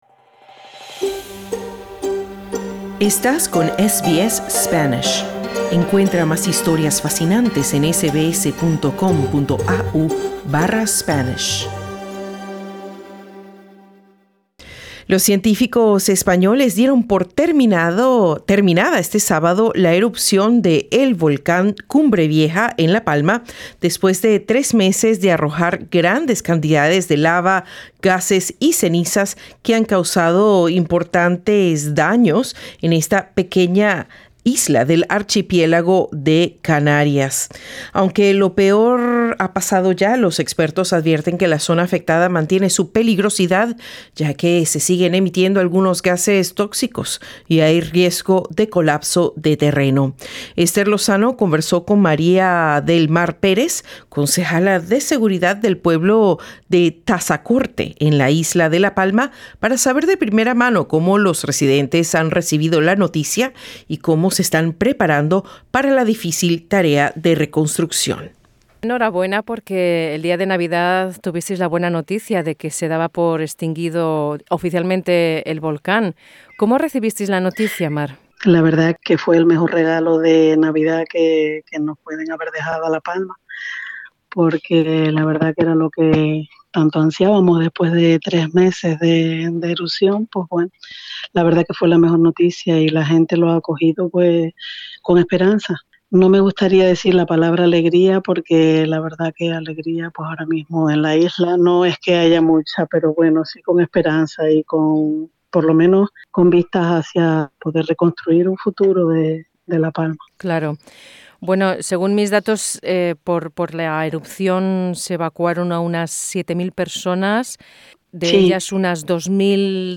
Los científicos españoles dieron por terminada este sábado la erupción del volcán Cumbre Vieja en La Palma, después de tres meses de arrojar grandes cantidades de lava, gases y cenizas que han causado importantes daños en esta pequeña isla del archipiélago de Canarias. La concejala de seguridad del pueblo de Tazacorte en la isla de La Palma, María del Mar Pérez, explica a SBS Spanish cómo los isleños se están preparando para la difícil tarea de la reconstrucción.